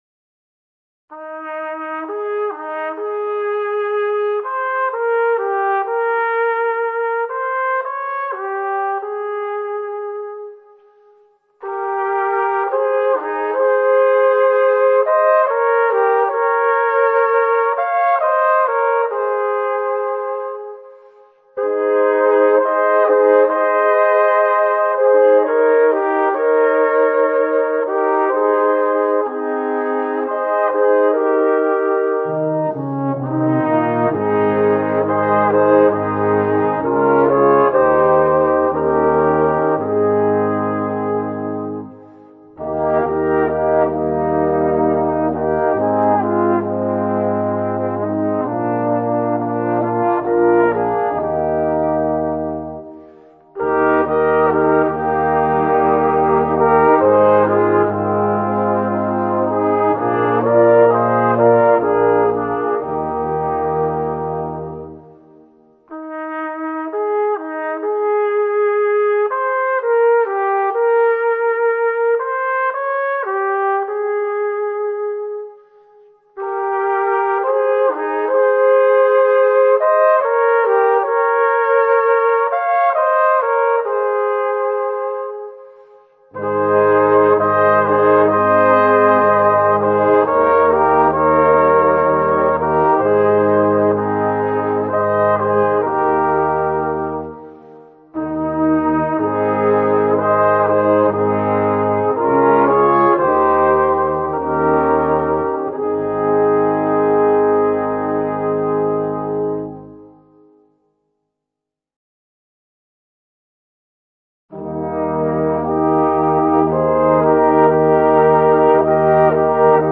Gattung: für 4-5 Blechbläser
20 Volksweisen für 4-5 Blechbläser
Flügelhorn in B                   1.
Tenorhorn / 1. Posaune in B    3.
Horn in F                               3.
Tuba in C                               5.